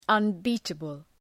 Προφορά
{ʌn’bi:təbəl}